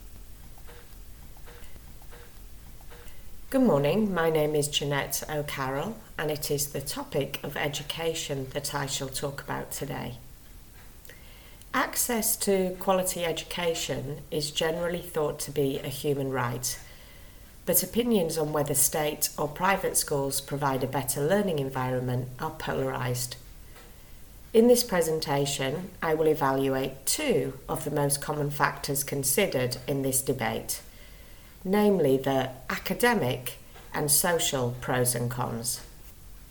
• Exam-ready recorded monologue (MP3)
c1-eoi-monologue-education-sneak-peek.mp3